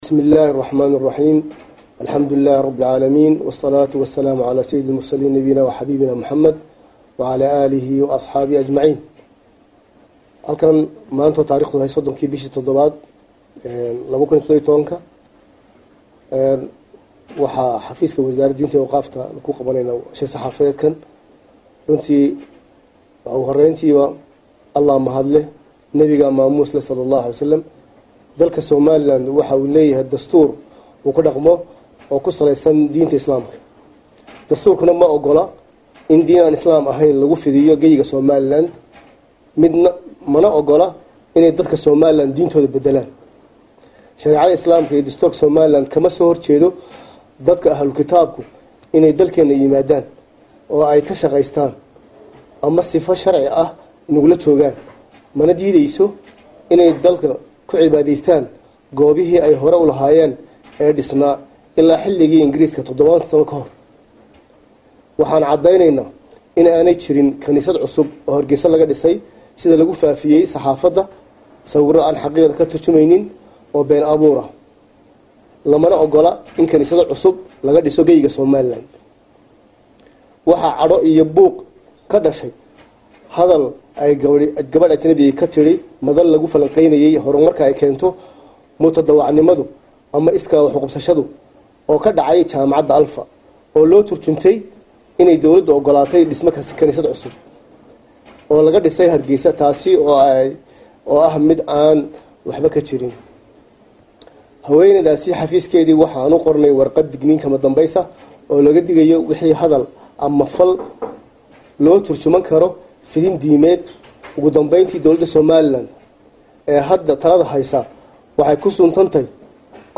Dhagayso Cod: Maamulka Soomaaliland oo si adag uga hadlay Kaniisadii laga furayay Magaalada Hargeysa | Goobsan Media Inc
Wasiirka diinta iyo Aawqaafta Maamulka Soomaaliland Khaliil Cabdilaahi Axmed oo Warbaahinta kula hadlayay Magaalada Hargeysa ayaa sheegay in Warka sheegaya in Kaniisad laga dhisay Magaalada Hargeysa ay tahay mid been abuur ah oo dad qaas ah ay soo abaabuleen.